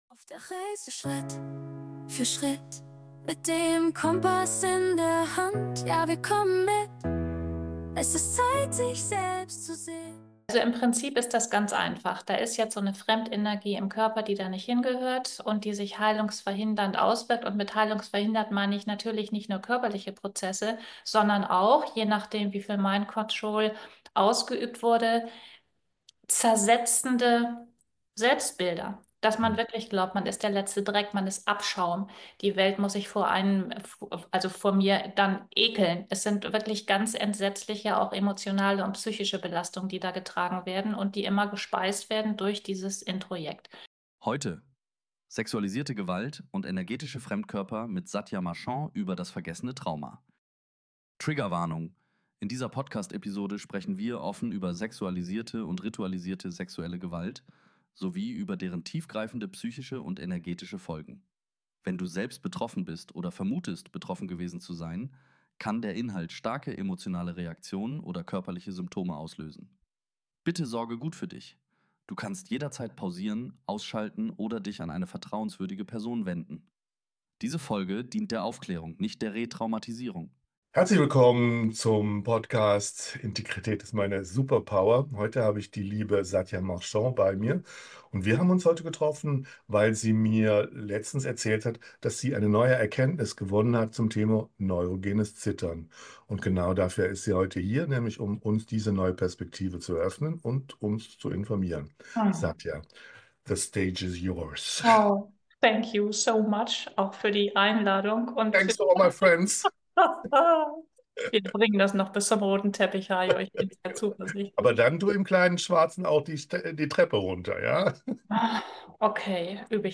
In diesem Interview erfährst du von einem unsichtbaren Fremdkörper, der jede Heilung sabotieren kann.